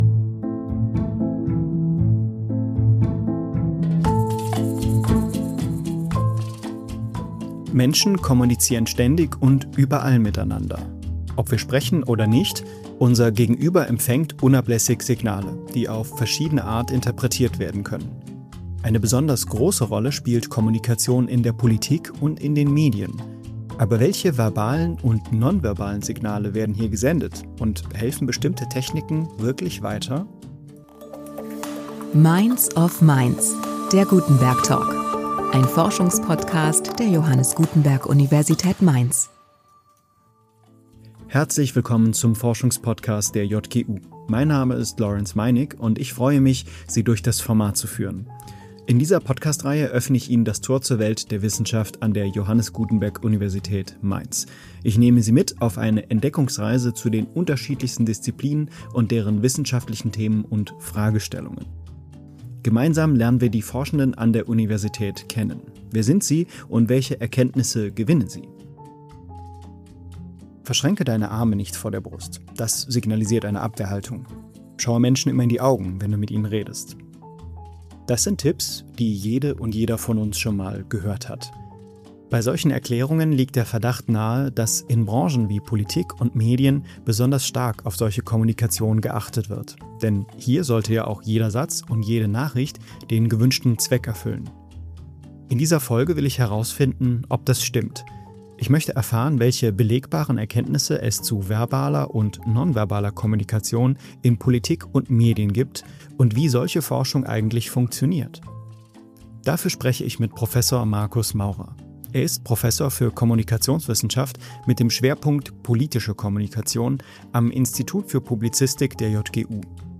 Welchen Einfluss haben beide Faktoren auf die politische wie mediale Kommunikation, welche belegbaren Erkenntnisse gibt es in der Wissenschaft dazu? Der Kommunikationswissenschaftler